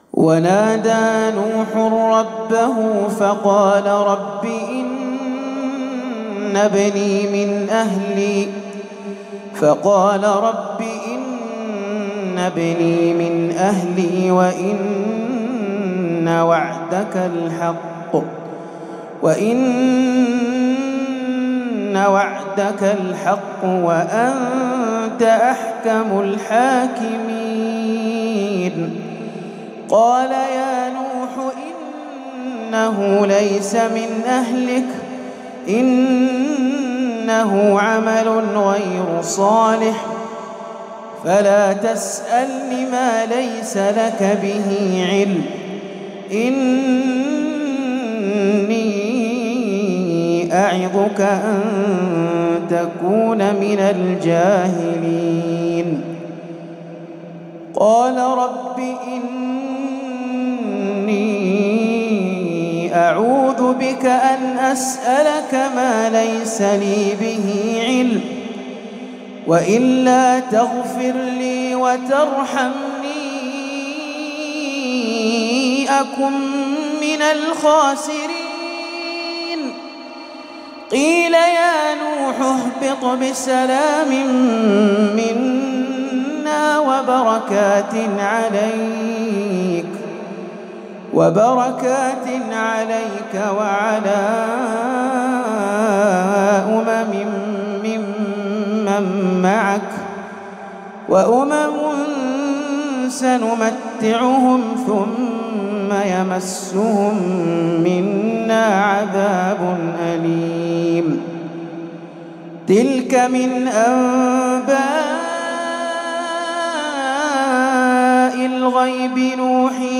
تلاوة عطرة من سورة هود
تلاوة من سورة هود للقارئ